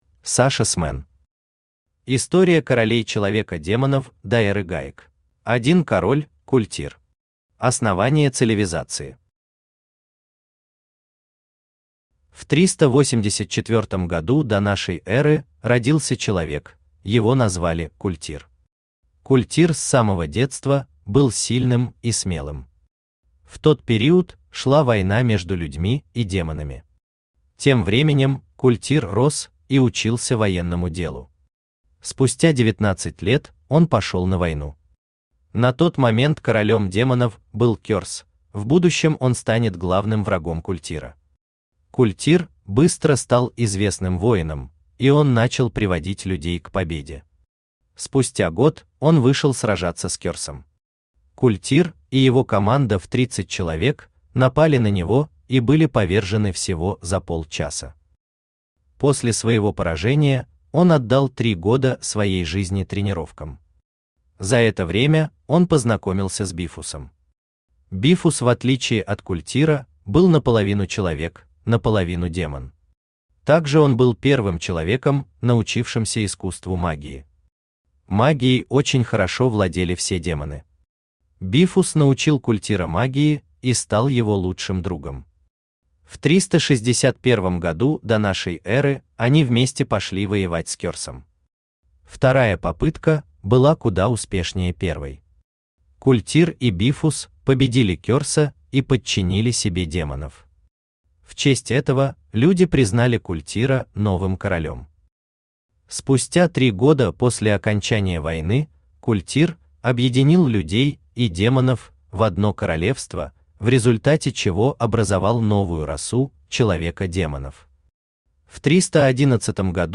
Аудиокнига История королей человеко-демонов до эры гаек | Библиотека аудиокниг